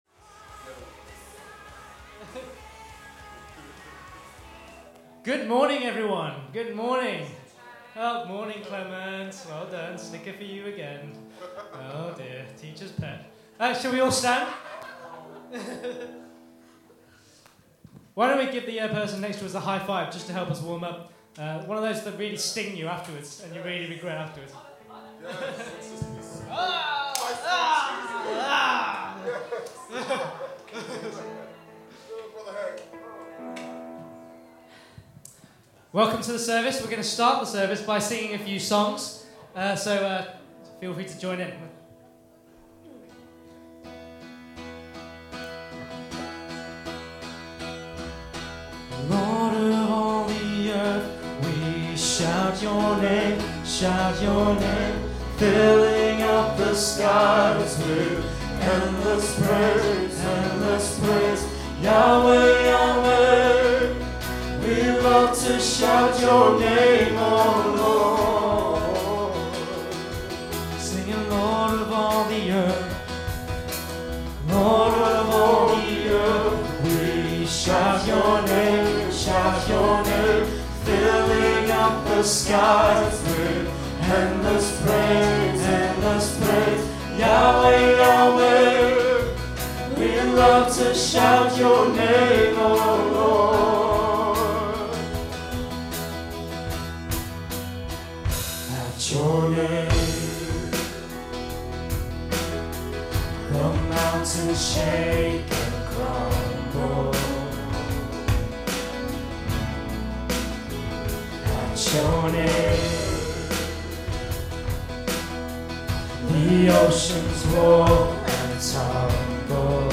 Worship January 25, 2015 – Birmingham Chinese Evangelical Church